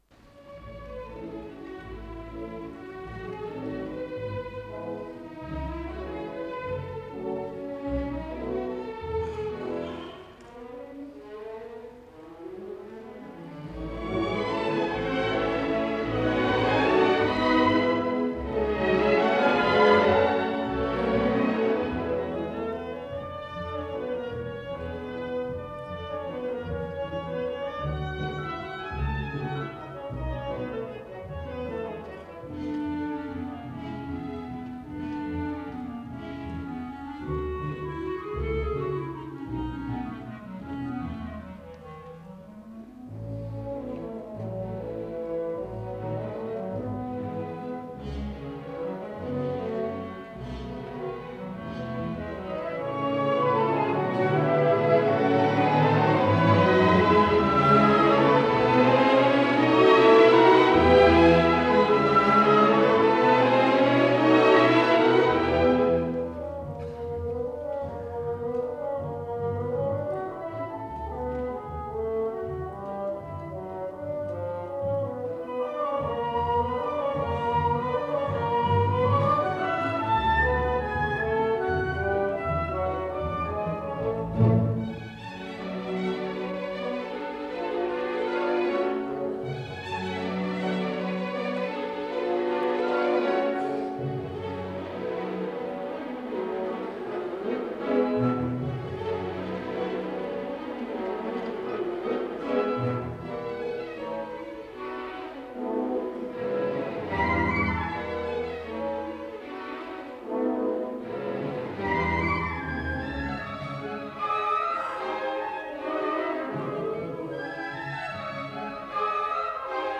ПКС-07449 — Симфония №5 — Ретро-архив Аудио
Исполнитель: Симфонический оркестр Ленинградской филармонии